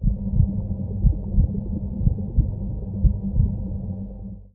Minecraft Version Minecraft Version latest Latest Release | Latest Snapshot latest / assets / minecraft / sounds / block / conduit / ambient.ogg Compare With Compare With Latest Release | Latest Snapshot